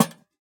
Minecraft Version Minecraft Version snapshot Latest Release | Latest Snapshot snapshot / assets / minecraft / sounds / block / lantern / place4.ogg Compare With Compare With Latest Release | Latest Snapshot